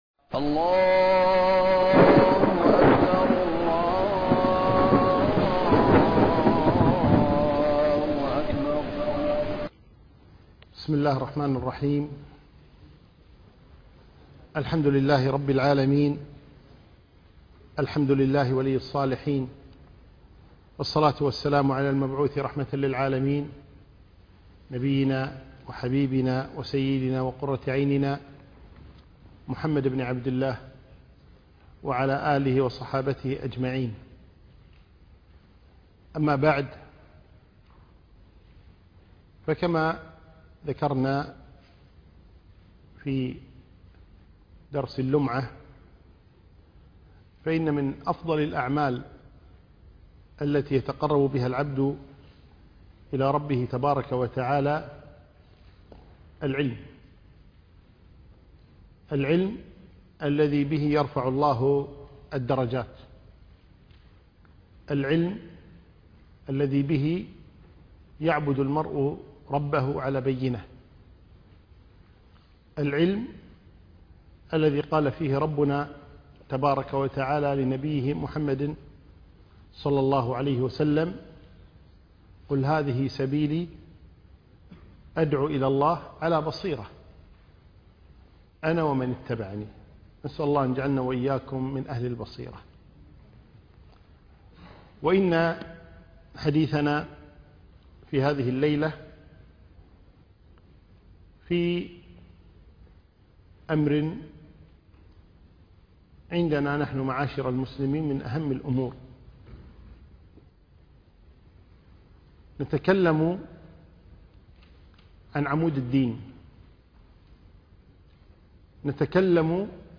الدرس الأول - فقه الصلاة